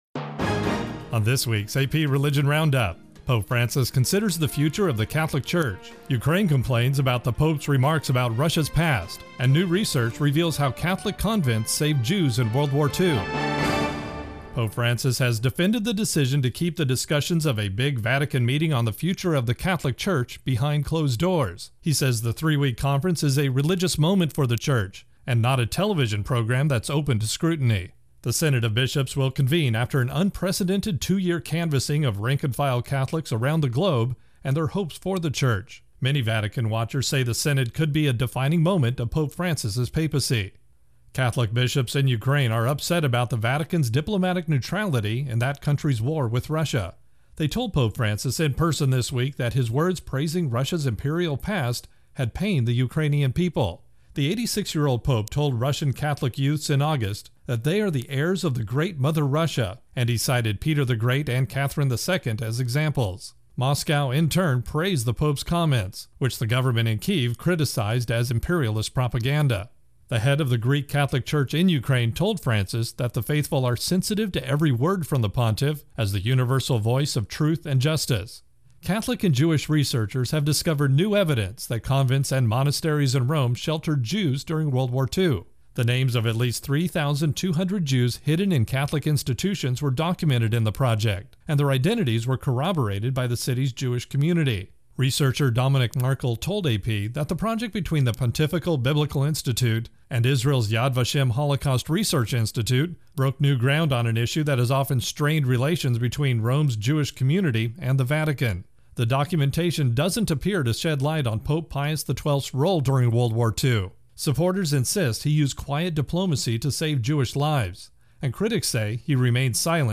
Intro + voicer for AP Religion Roundup